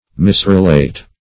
Misrelate \Mis`re*late"\, v. t. To relate inaccurately.